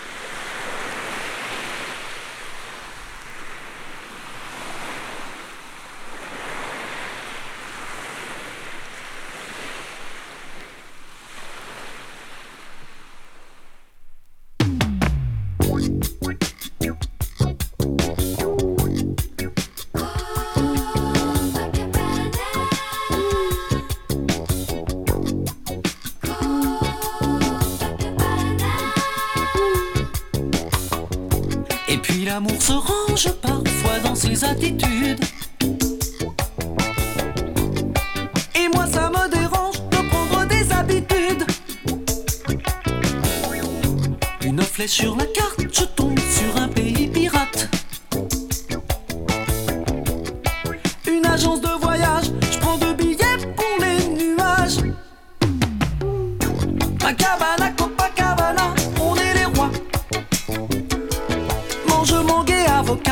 波の音から始まる3曲+波そのもののSE(うっすらA-1曲が聴こえる仕様)を収録したオモロ仕様！